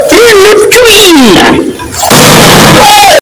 Le meme de "Une Tuille" mais en bast-boosted....
une-tuille-bast-boosted.mp3